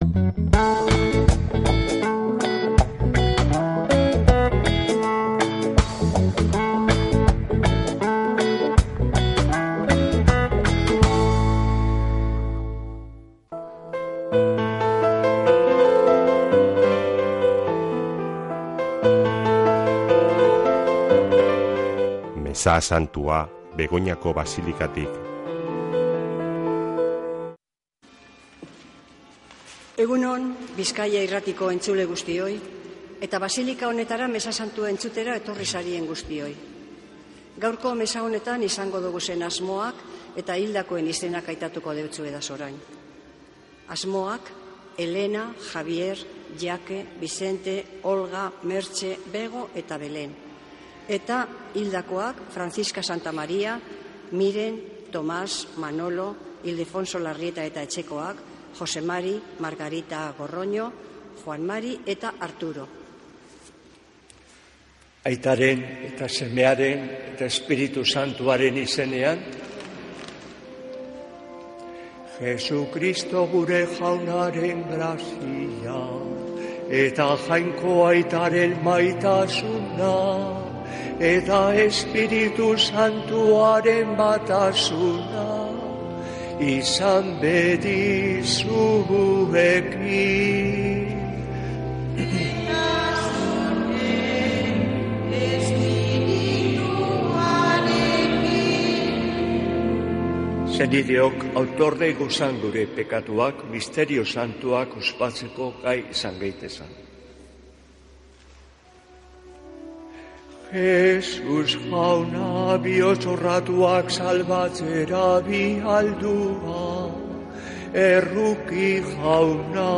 Mezea Begoñako Basilikatik | Bizkaia Irratia
Mezea (26-04-21)